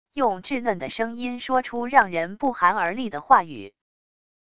用稚嫩的声音说出让人不寒而栗的话语……wav音频